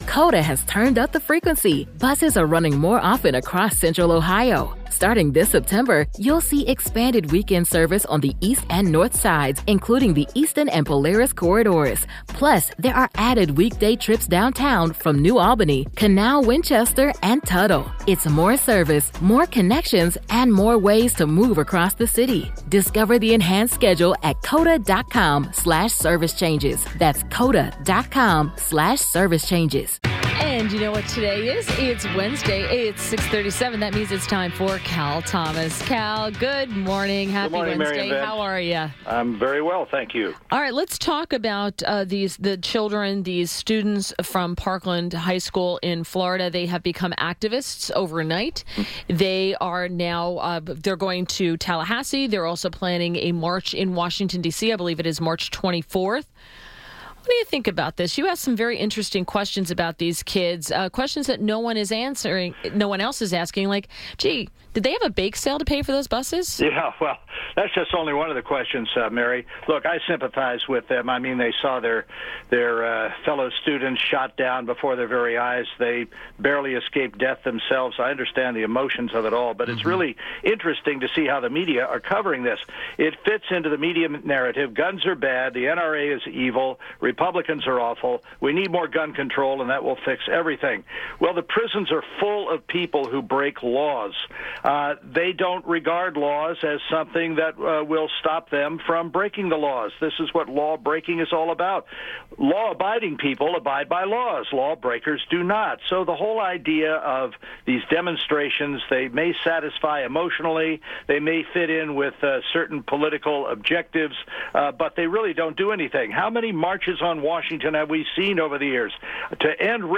INTERVIEW - CAL THOMAS - syndicated columnist – discussed student activists from Florida school shooting, upcoming DC anti-gun marches, and donors for these activists